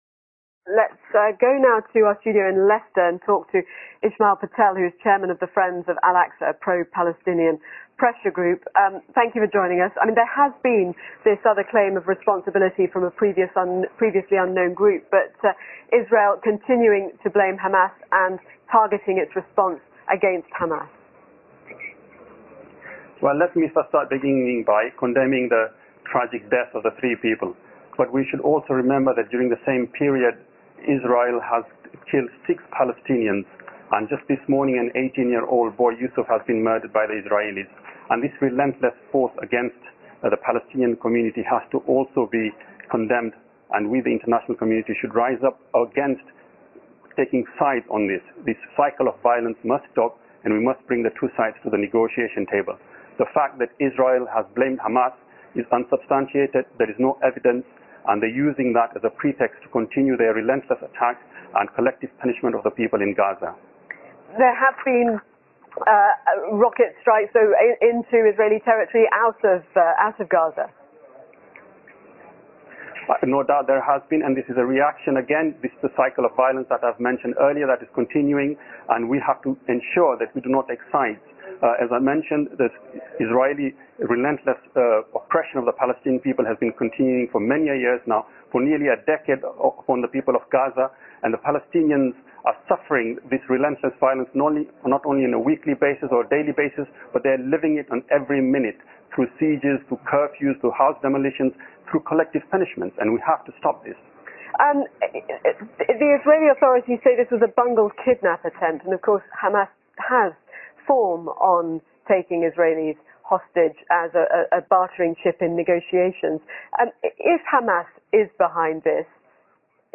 BBC news,以色列巴勒斯坦冲突升级